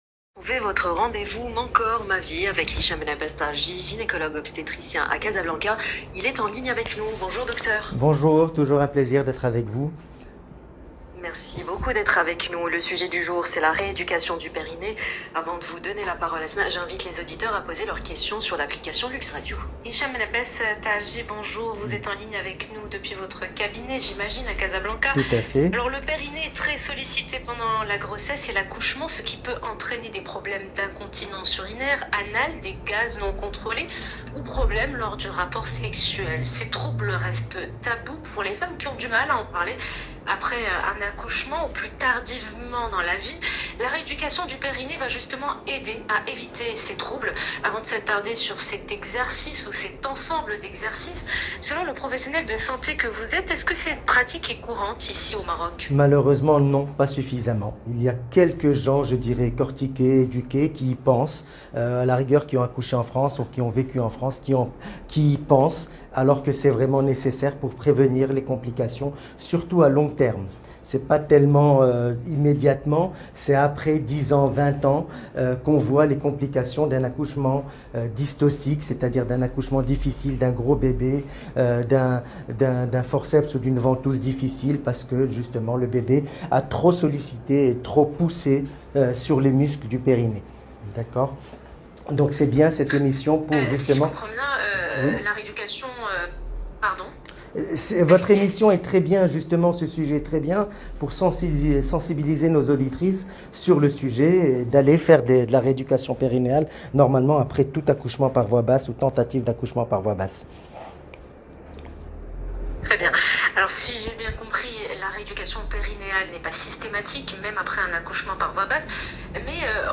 J’insiste sur l’intérêt de cette rééducation périnéale post-accouchement dans mon interview dans l’Heure Essentielle diffusée sur Luxe Radio du mardi 15 septembre